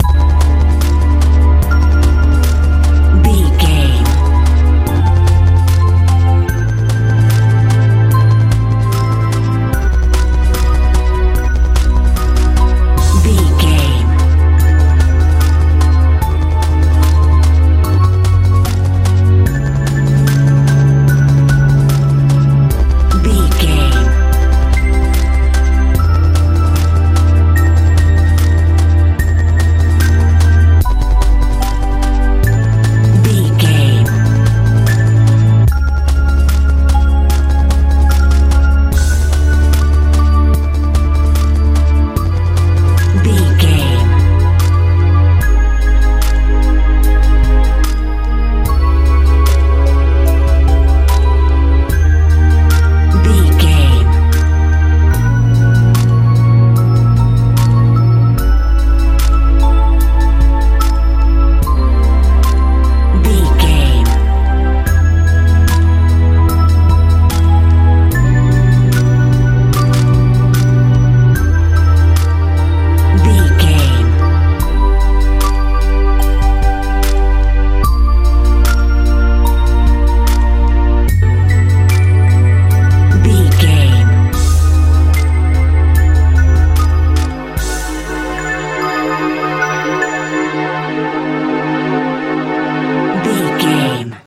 jpop feel
Aeolian/Minor
A♯
magical
strange
bass guitar
synthesiser
drums
80s
90s